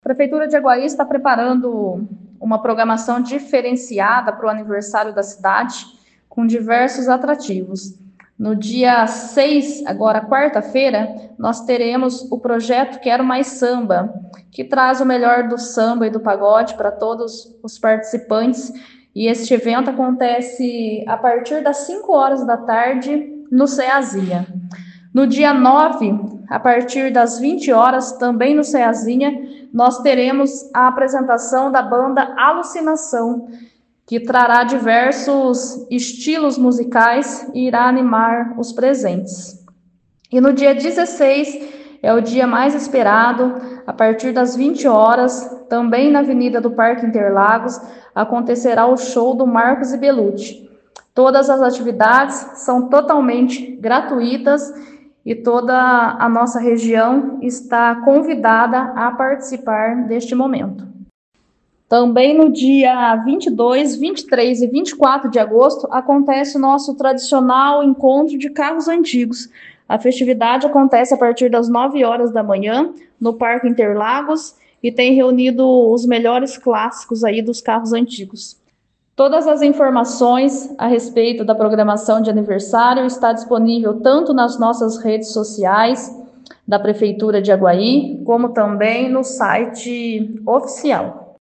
Em entrevista à 92FM